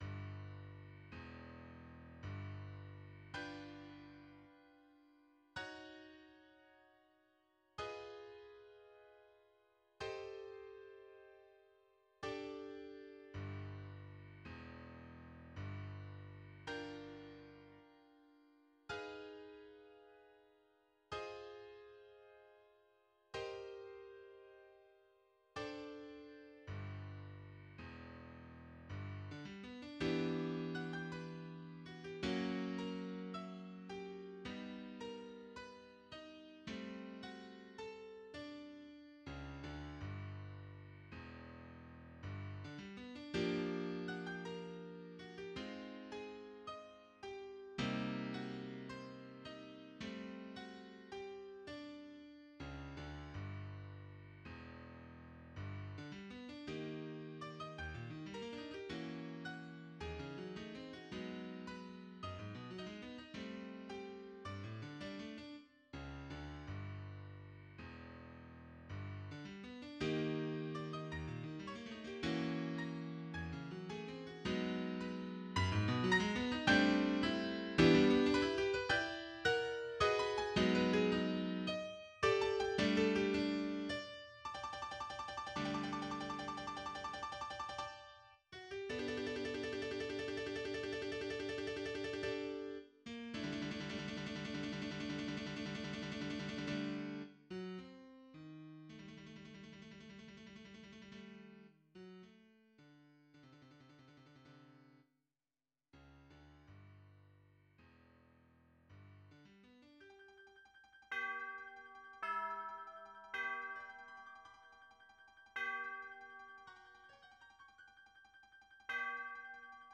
Prizor_1.MID (MIDI аудио датотека, трајање 5 м 48 с, 422 bps битрејт, величина: 18 kB)
I призор из опере Стевана Христића „Сутон” за глас и клавир (MIDI)